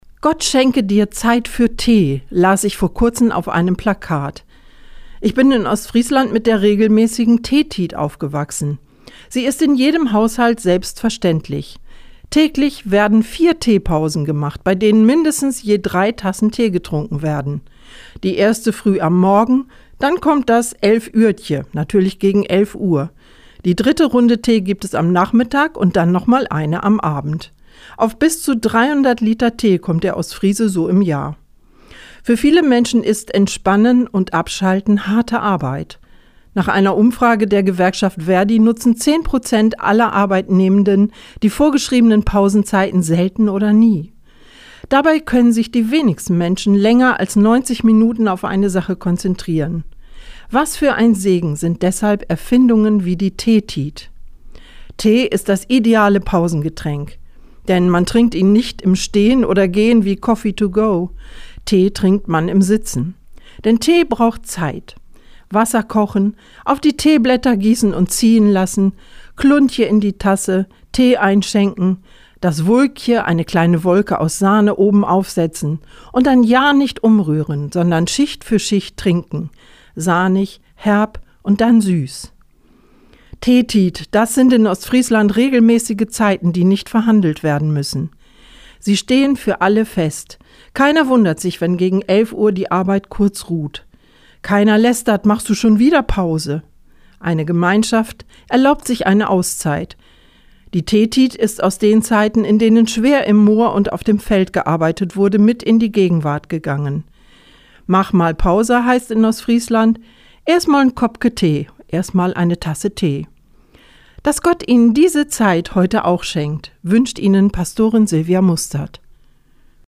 Radioandacht vom 10. August